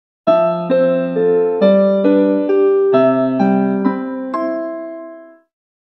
13. tour piano sim2 (по умолчанию)